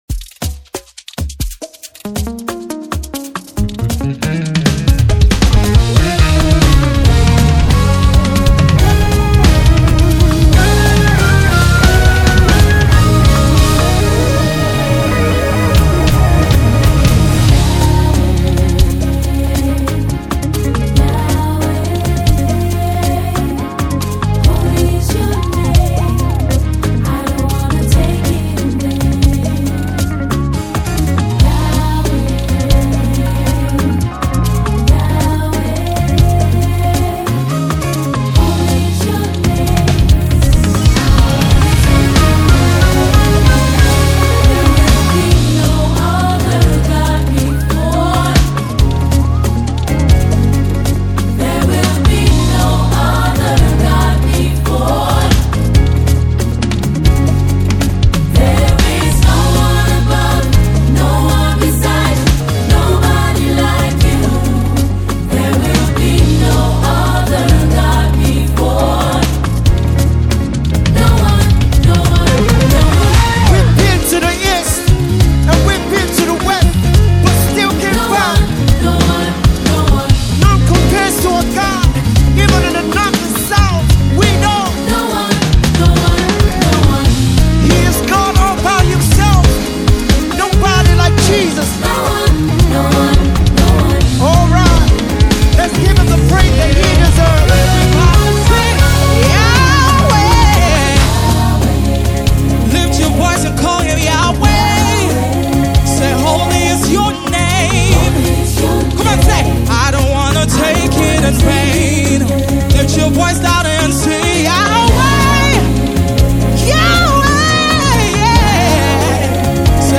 soul-stirring anthem